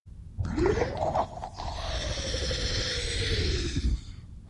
描述：The bloop is a sound recorded in summer 1997.To this day its still unknown what exactly caused the sound.
标签： Creature Scream original horror Roar Monster Growl Scary Mystery
声道立体声